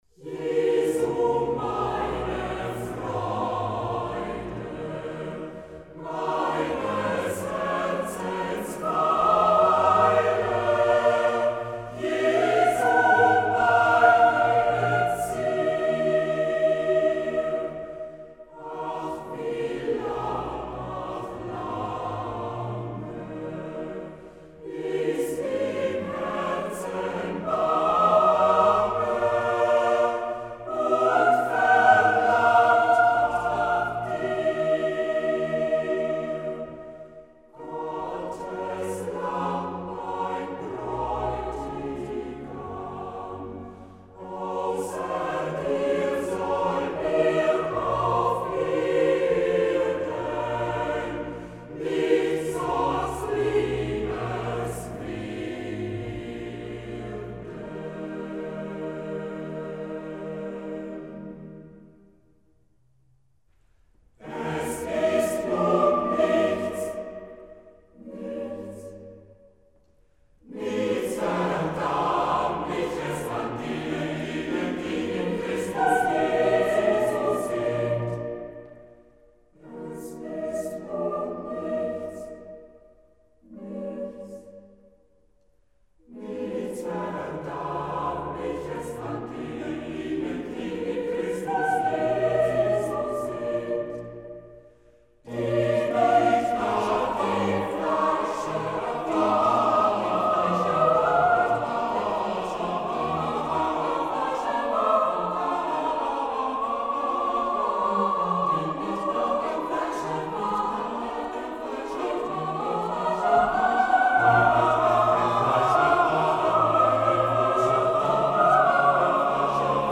Full Arrangement